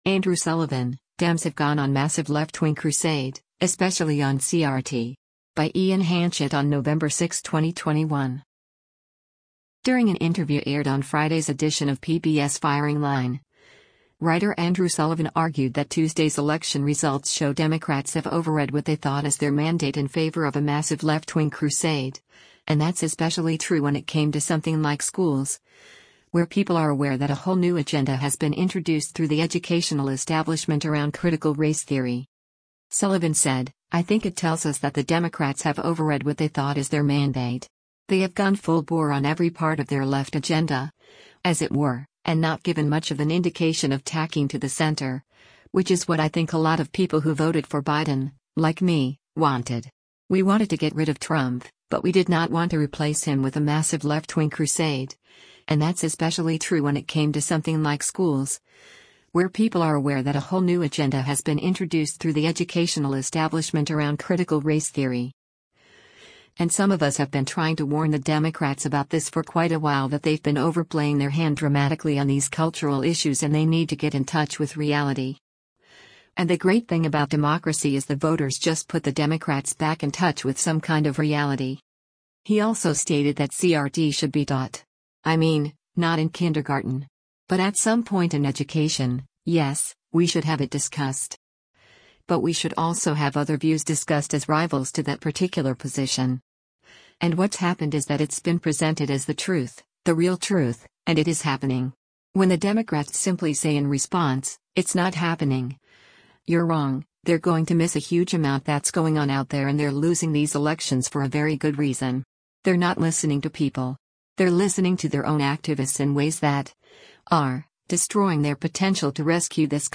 During an interview aired on Friday’s edition of PBS’ “Firing Line,” writer Andrew Sullivan argued that Tuesday’s election results show “Democrats have overread what they thought as their mandate” in favor of “a massive left-wing crusade, and that’s especially true when it came to something like schools, where people are aware that a whole new agenda has been introduced through the educational establishment around Critical Race Theory.”